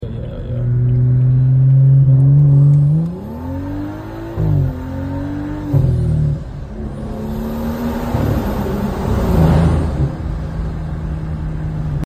N55 Low Boost Soundtrack 🔊🔥 Sound Effects Free Download